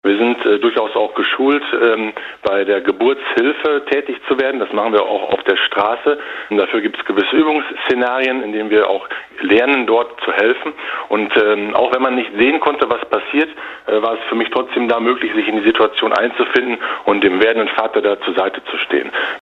Feuerwehrmann
feuerwehrmann_2.mp3